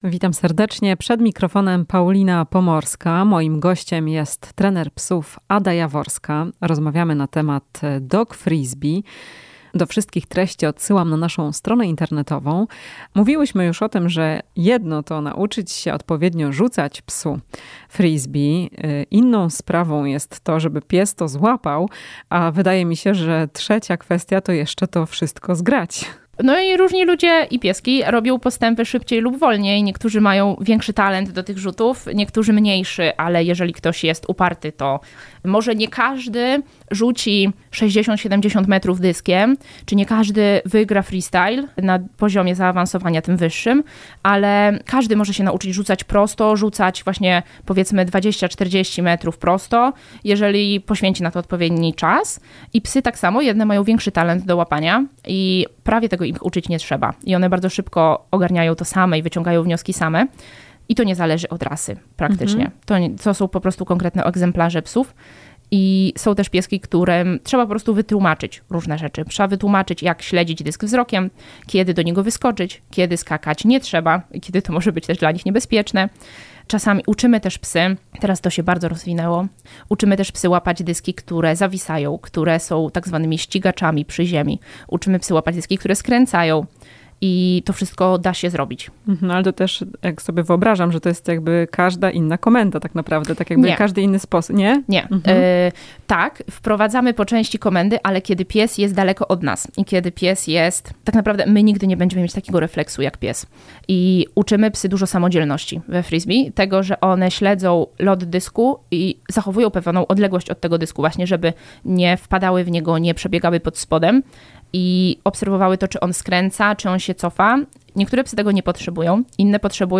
W „Chwili dla pupila” o rzutach w dogfirsbee w rozmowie